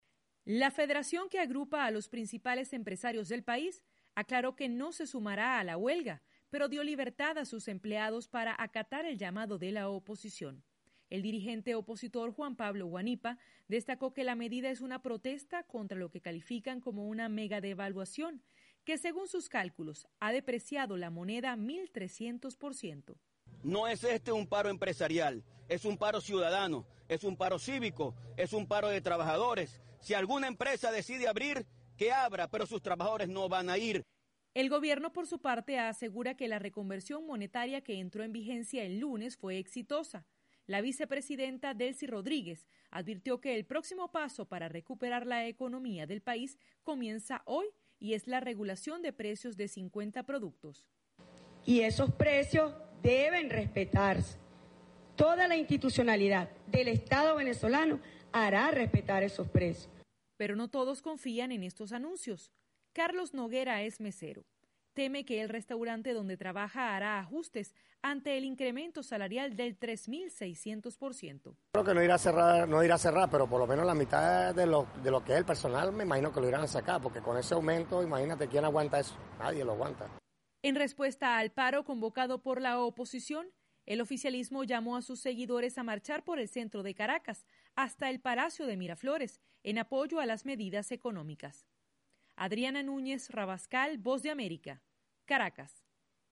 VOA: Informe de Venezuela